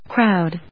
crowd /krάʊd/
• / krάʊd(米国英語)